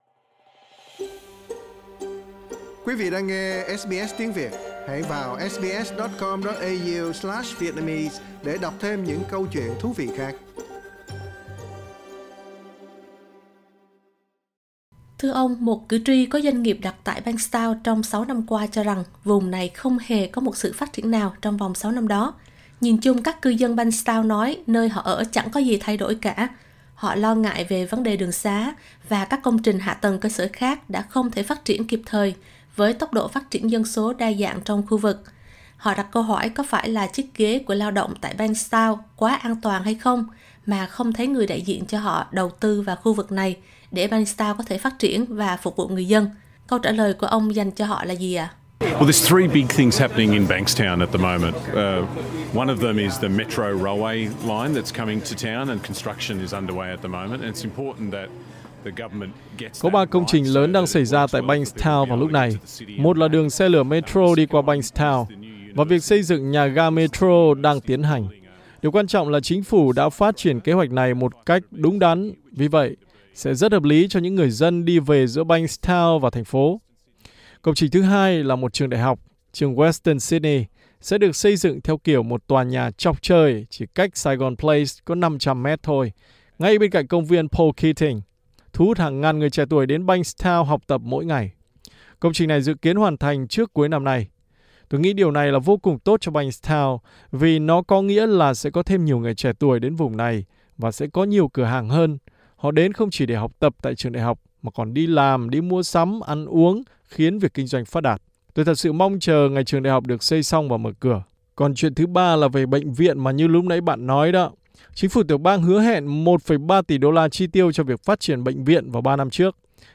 SBS Tiếng Việt đã có cuộc phỏng vấn Dân biểu thuộc đảng Lao Động Jason Clare tại cuộc đối thoại về bầu cử do SBS tổ chức ngày thứ Bảy vừa qua 23/4 tại Saigon Place, Bankstown.